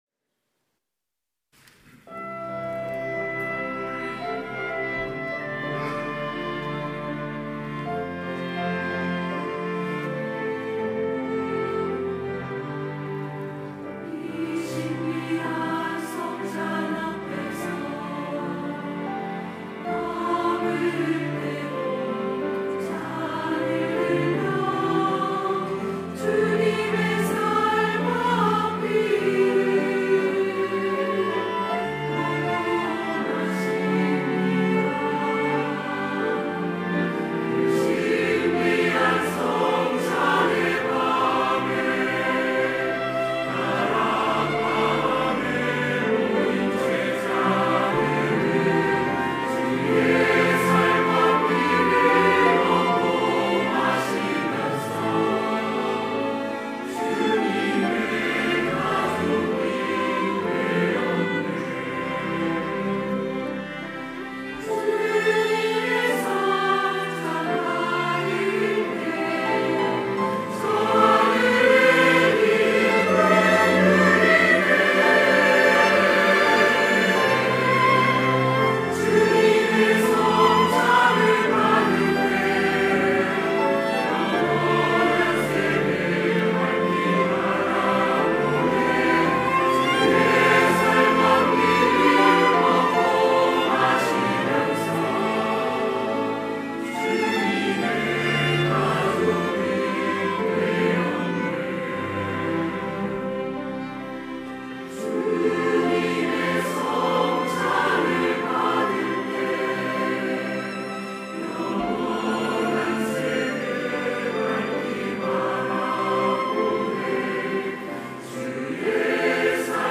호산나(주일3부) - 주님의 성찬
찬양대 호산나